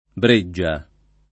Breggia [ br %JJ a ]